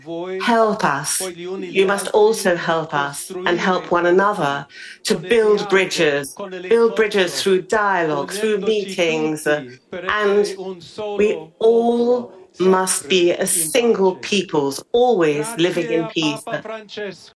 Addressing thousands who gathered in the Vatican, he called for unity and peace……….